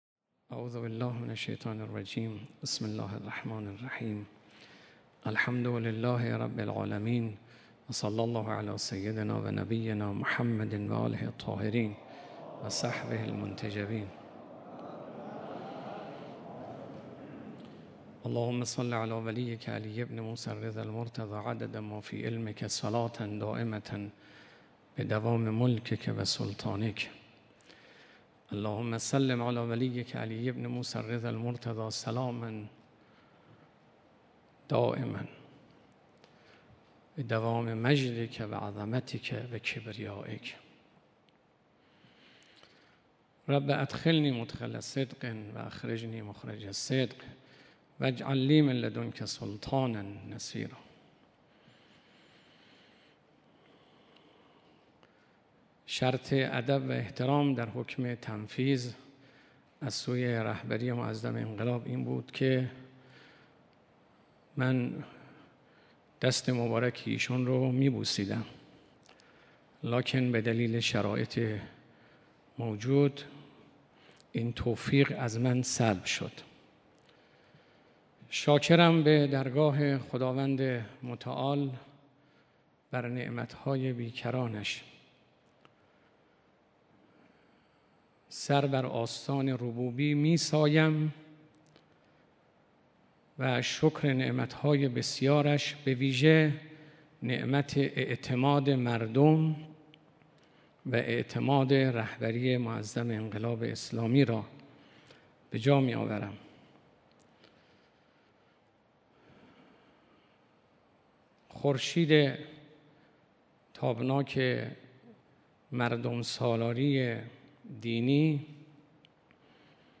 مراسم تنفیذ حکم ریاست جمهوری حجت‌الاسلام سیدابراهیم رئیسی
سخنرانی ریاست محترم جمهور جناب آقای حجت‌الاسلام رئیسی